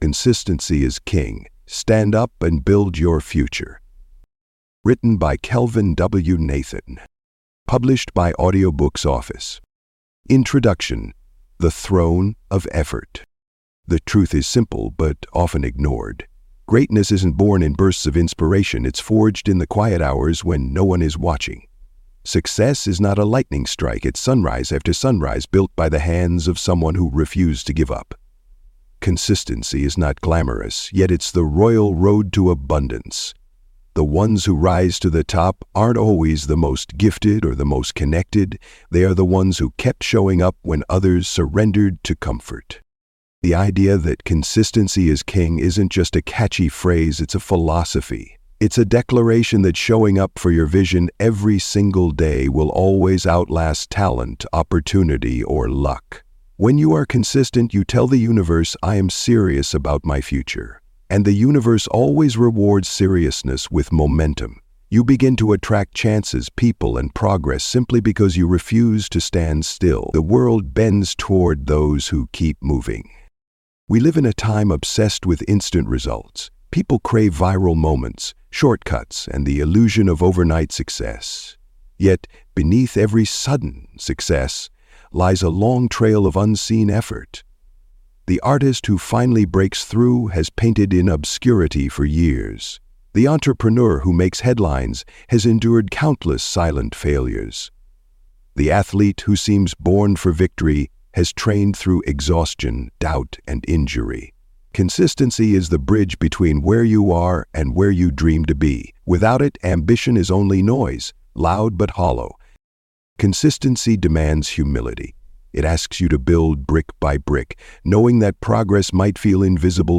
Do Hard Things Today for an Effortless Tomorrow (Audiobook)